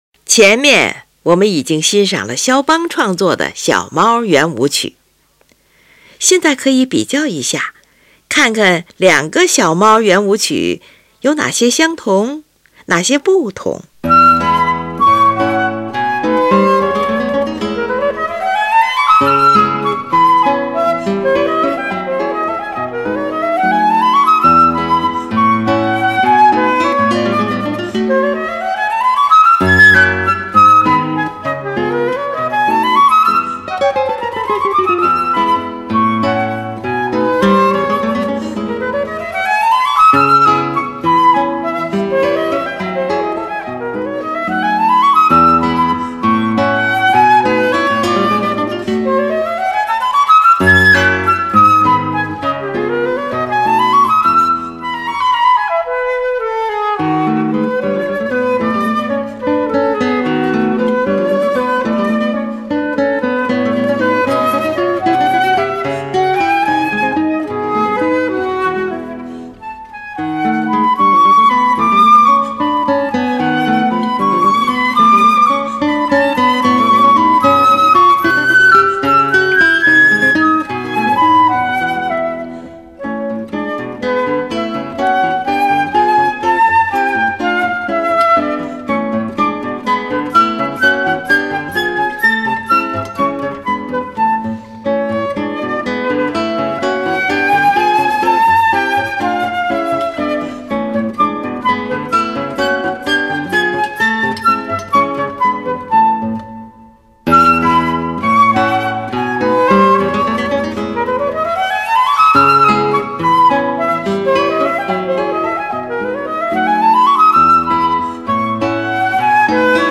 Suite for Flute and Guitar
这是一首由长笛与吉他合奏的乐曲，共分为四个乐章。
第二乐章，这是一个活泼的小快板乐章。